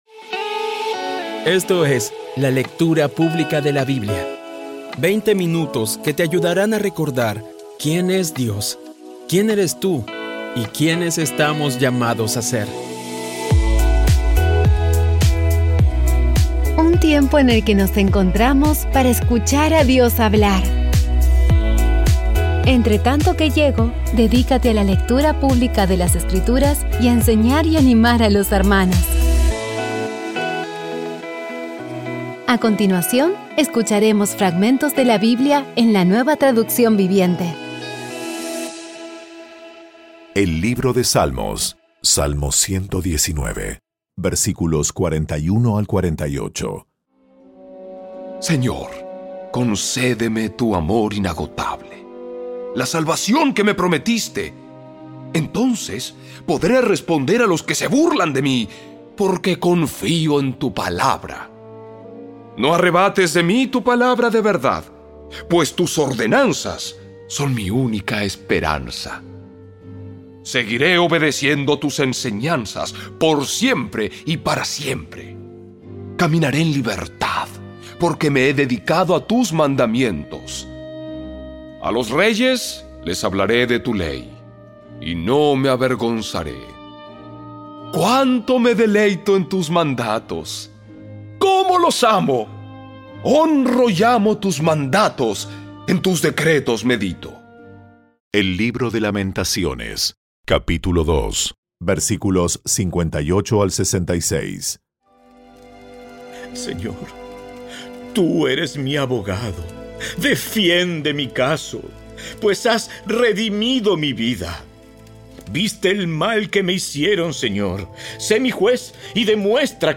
Audio Biblia Dramatizada Episodio 301
Poco a poco y con las maravillosas voces actuadas de los protagonistas vas degustando las palabras de esa guía que Dios nos dio.